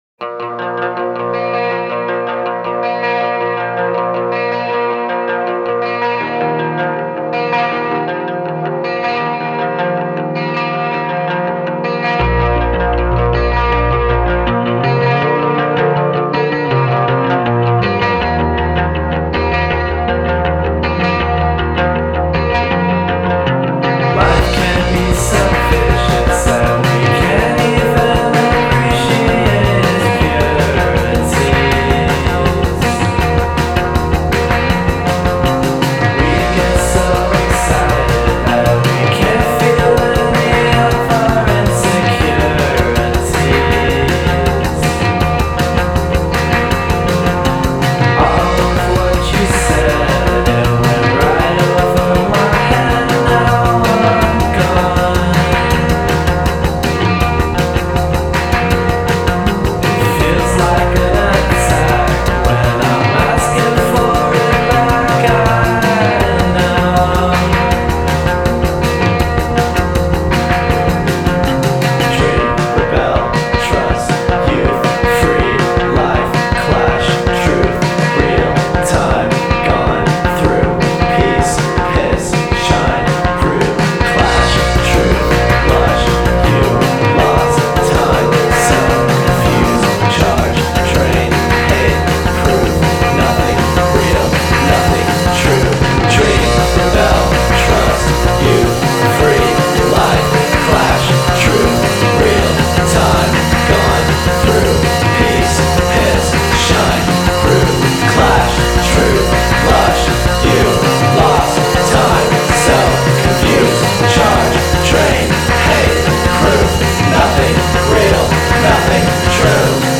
lieve, quasi assente, ma sicura sulla limpida melodia.
Tutto dà l'impressione di essere in movimento febbrile.